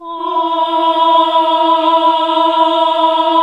AAH E2 -L.wav